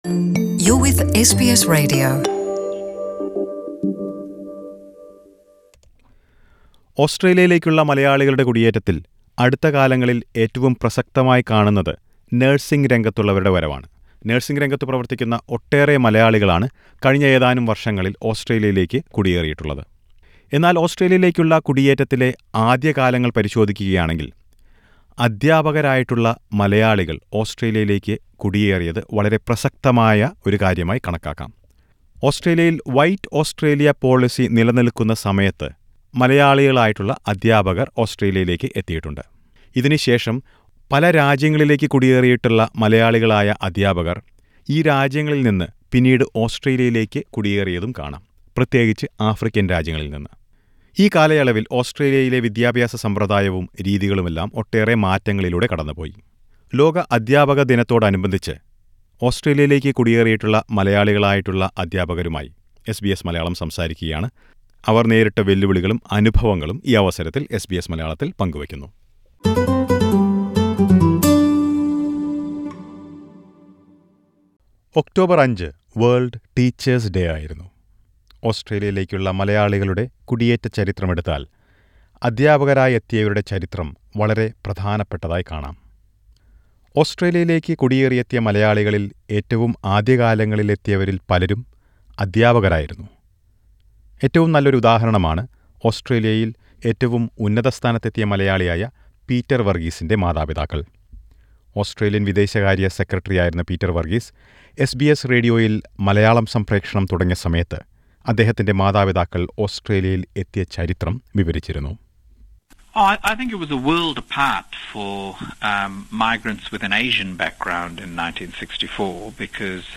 Migration of teachers to Australia has a significant place in the history of Australian malayalee migration to Australia. SBS Malayalam speaks to a few teachers about their experiences in settling in Australia.